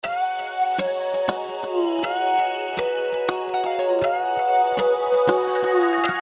Никто не знает, что за синт?